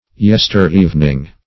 Search Result for " yester-evening" : The Collaborative International Dictionary of English v.0.48: Yestereve \Yes"ter*eve`\, Yester-evening \Yes"ter-e`ven*ing\, n. The evening of yesterday; the evening last past.